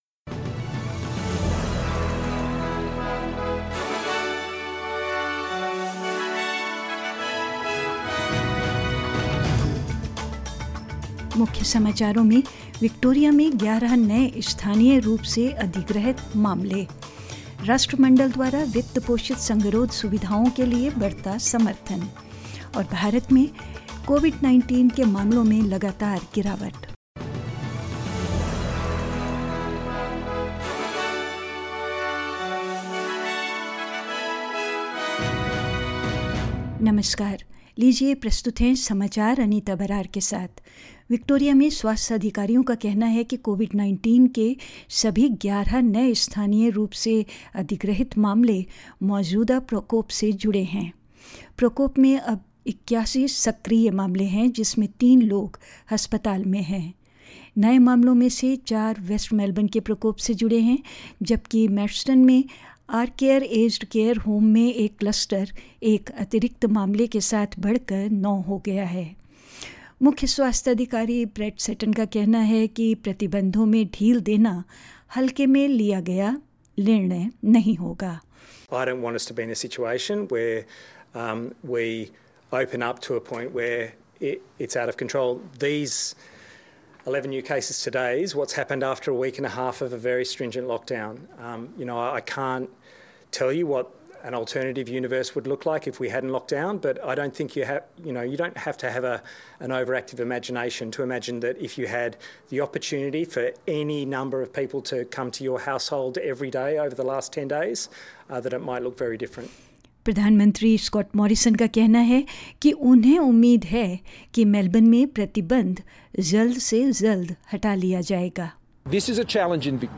In this latest SBS Hindi News bulletin of Australia and India: Victoria records 11 new local COVID-19 cases in the 24 hours to midnight on Sunday; India reports the lowest new cases of coronavirus in 61 days and more.